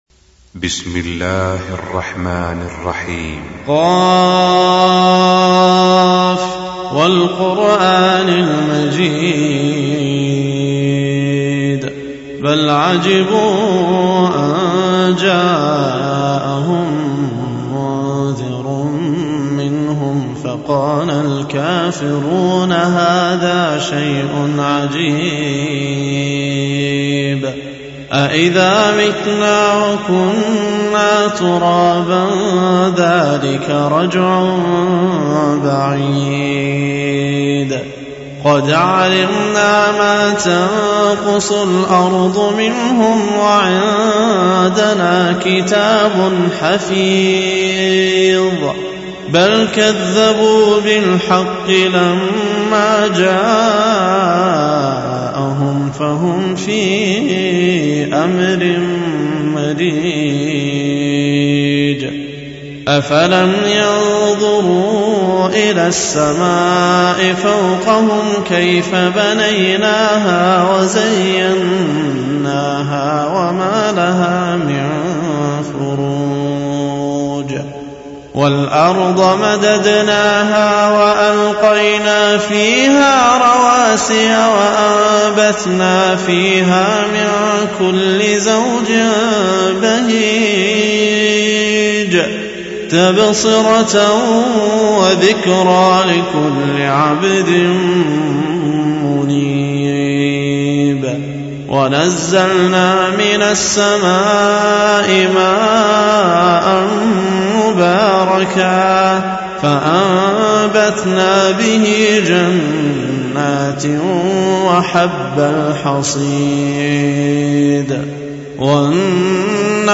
تلاوة نادرة لسورة ق
مدري كان الصوت متغير شويتين ؟؟؟